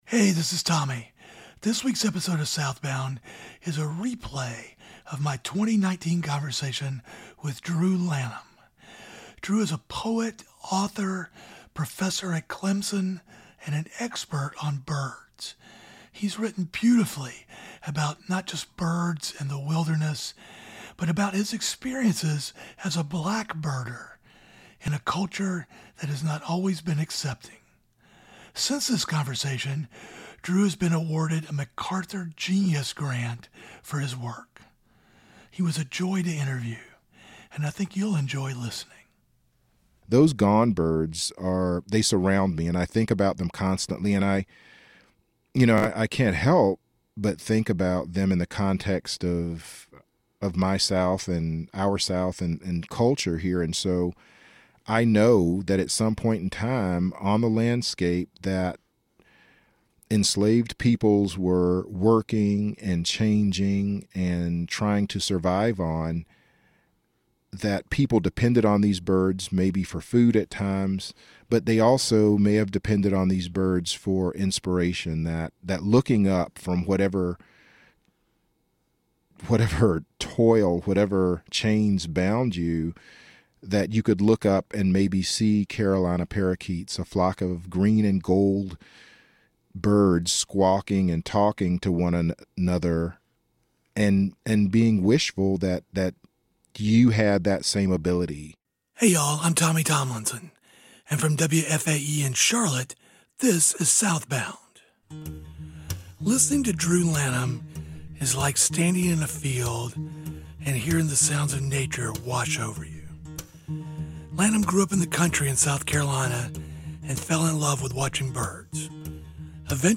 SouthBound, a new podcast series from WFAE, talks to people who were born and raised in the South.